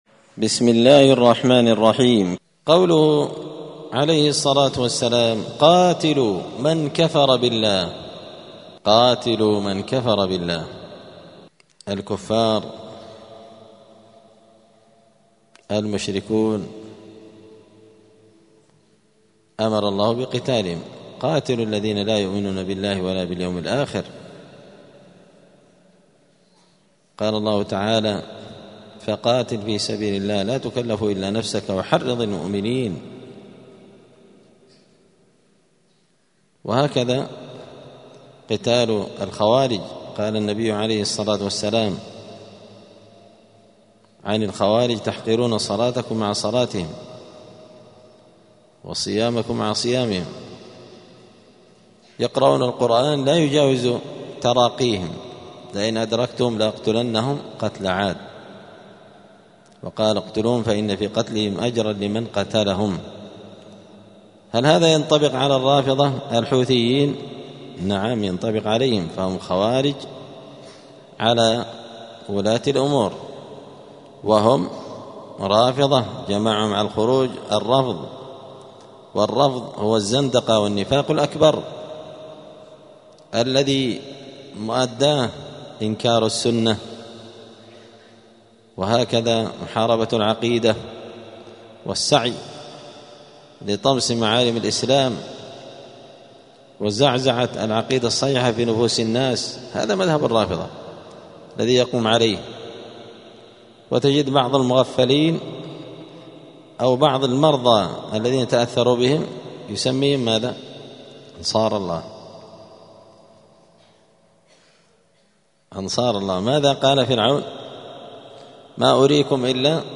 دار الحديث السلفية بمسجد الفرقان قشن المهرة اليمن
*الدرس الخمسون بعد المائة (150) {تابع لباب ما جاء في ذمة الله وذمة نبيه}*